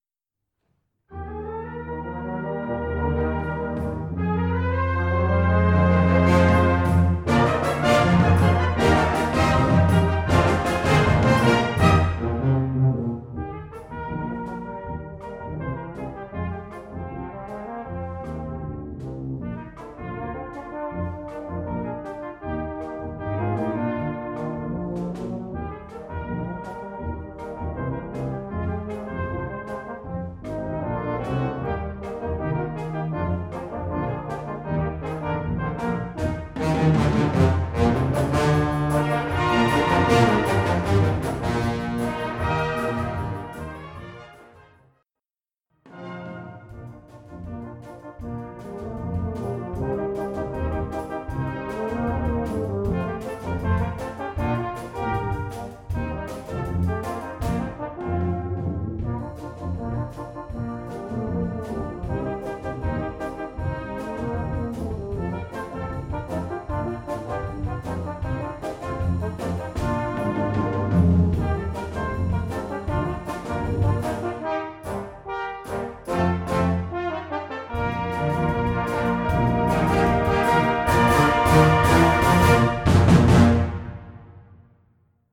Duo pour Bb-Eb (Sib-Mib)/ Bb-Bb (Sib-Sib) / Eb-Eb (Mib-Mib)
Disponible en instrumentation Brass Band
2 _ facile _einfach _ Easy
Brass Band
Duo & Brass Band